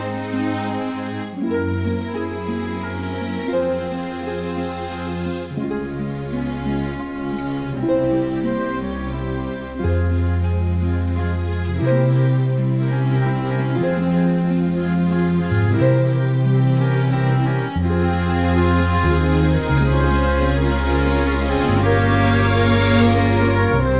no Backing Vocals Musicals 3:50 Buy £1.50